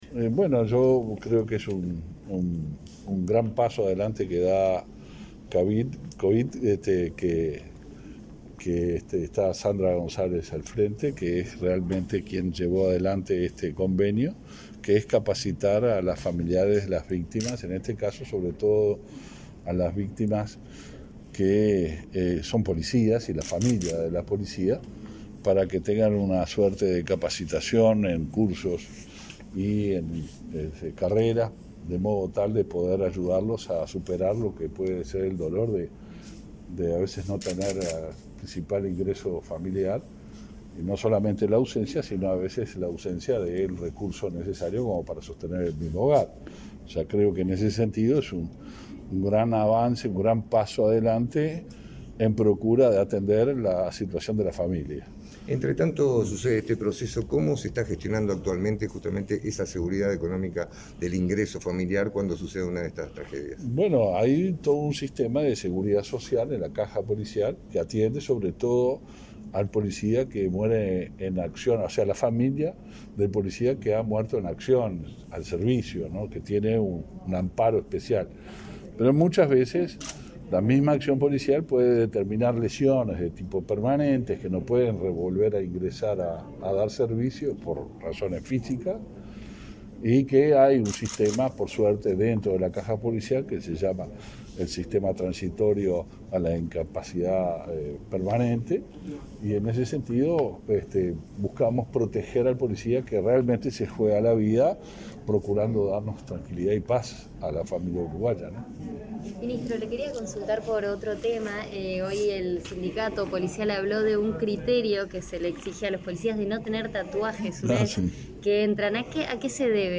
Declaraciones del ministro del Interior, Luis Alberto Heber
El ministro del Interior, Luis Alberto Heber, realizó declaraciones este martes 10, en el marco de la firma de convenio entre su cartera e Inefop,